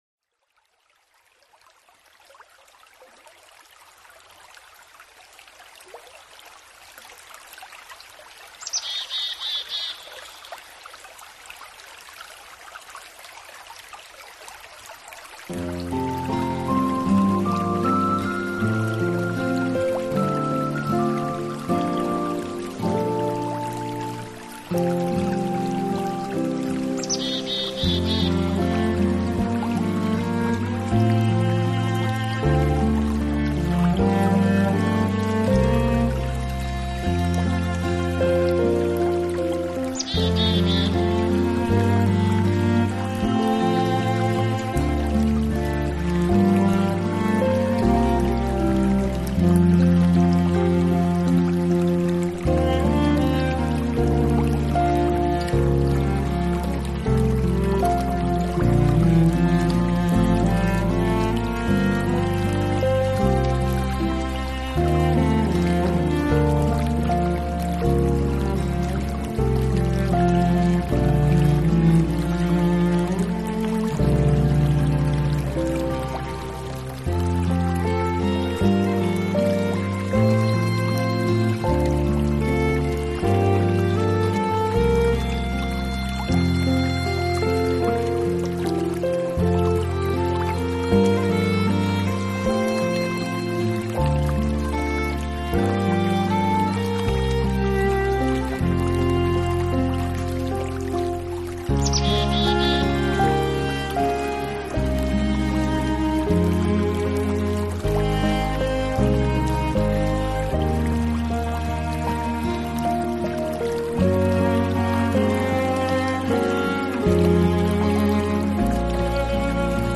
这张专辑主要的旋律集中早大提琴中，再加上一些钢琴和吉他的悠扬伴奏，
当然少不了来自自然的声响（小鸟的歌唱，细细的雨声……），完全给你一个在
秋天成熟放松的感觉... ...